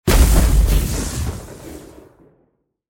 دانلود آهنگ آتش 25 از افکت صوتی طبیعت و محیط
دانلود صدای آتش 25 از ساعد نیوز با لینک مستقیم و کیفیت بالا
جلوه های صوتی